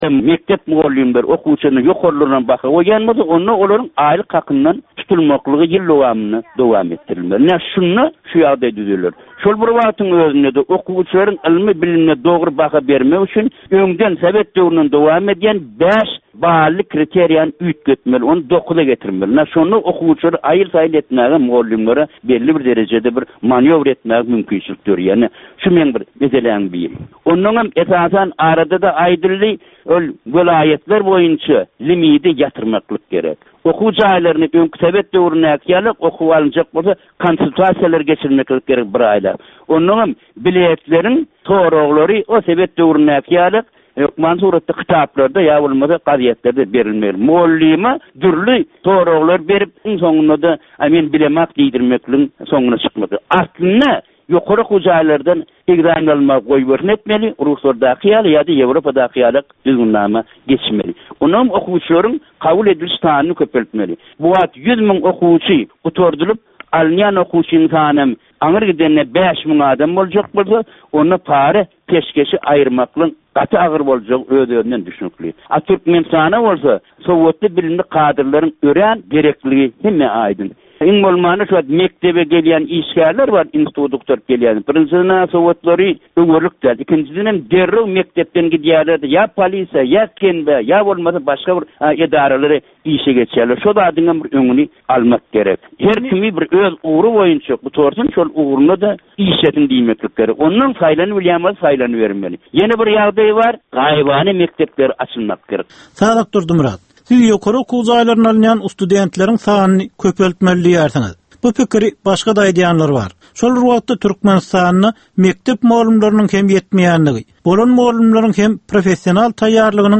Türkmen jemgyýetindäki döwrün meseleleri. Döwrün anyk bir meselesi barada 10 minutlyk ýörite syn-geplesik. Bu geplesikde dinleýjiler, synçylar we bilermenler döwrün anyk bir meselesi barada pikir öwürýärler, öz garaýyslaryny we tekliplerini orta atýarlar.